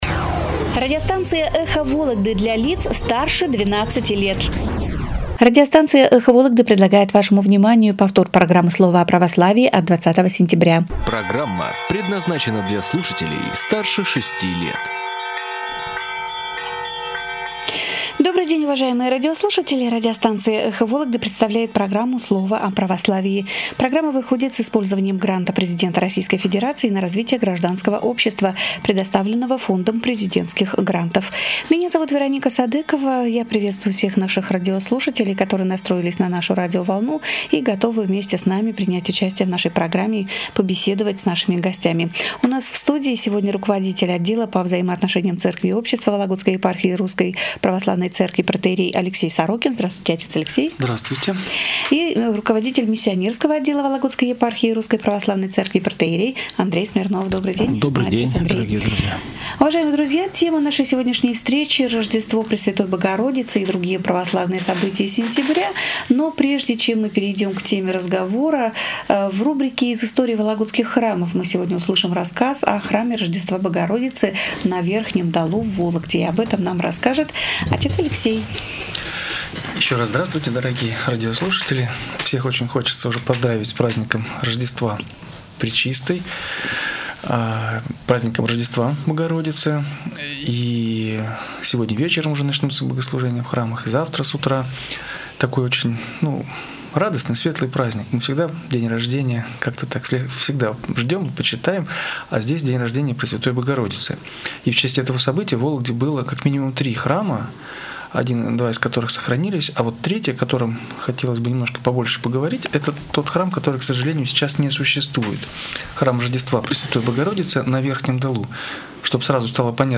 В очередном эфире программы "Слово о Православии" священники рассказали о значимых православных датах сентября, о праздниках Рождества Пресвятой Богор...